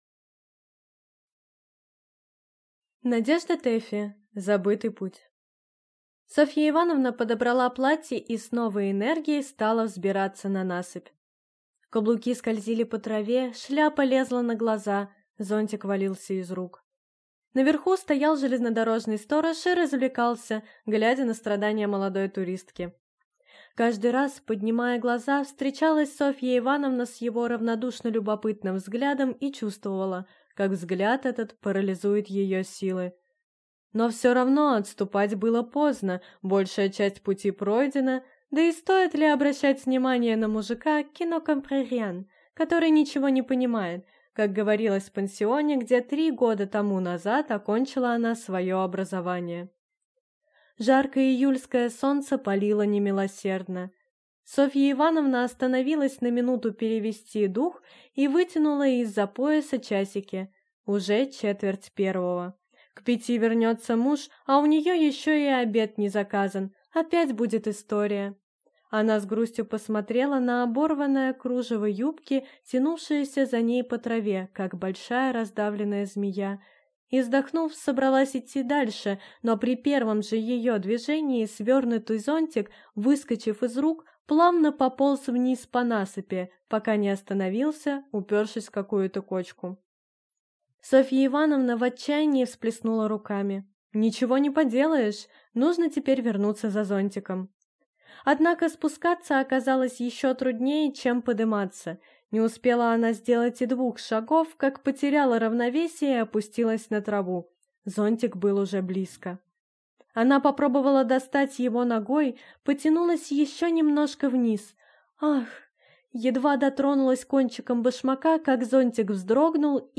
Аудиокнига Забытый путь | Библиотека аудиокниг
Прослушать и бесплатно скачать фрагмент аудиокниги